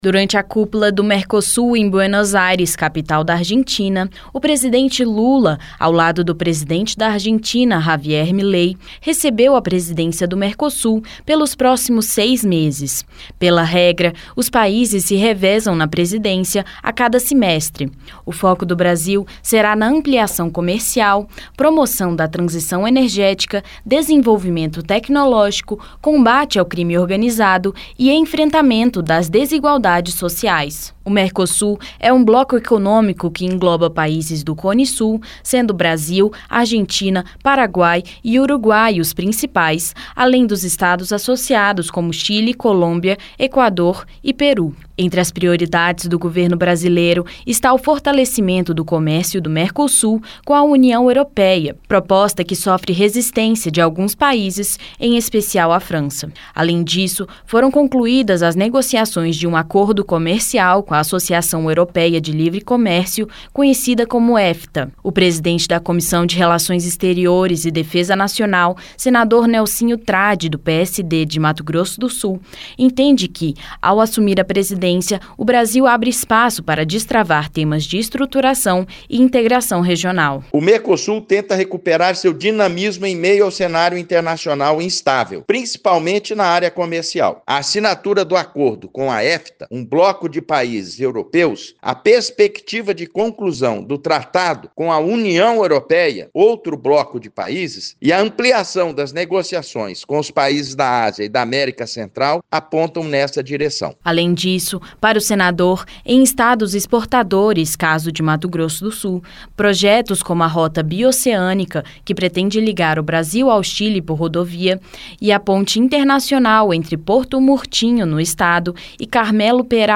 Rádio Senado : Notícias.